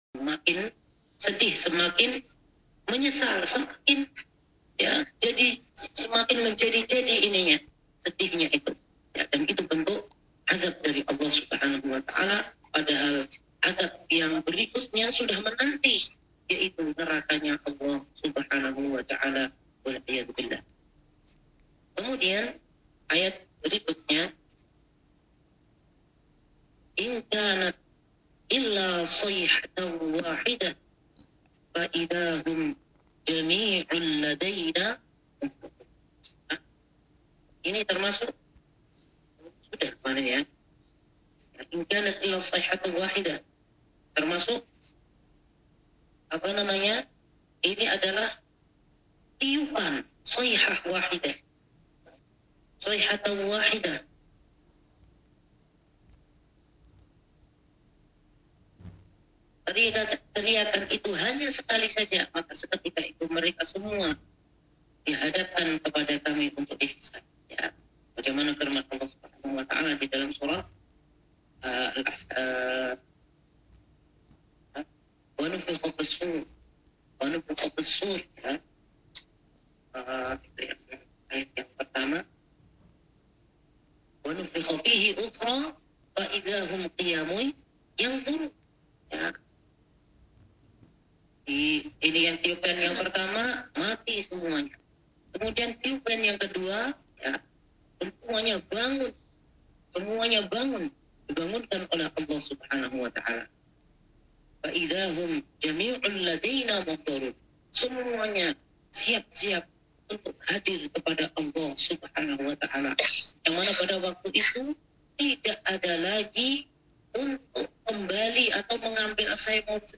Kajian Senin Pagi – Wakra Penceramah